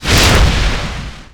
Dragon Breath Fire Sound
horror